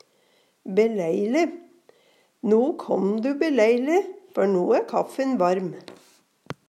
beleile - Numedalsmål (en-US)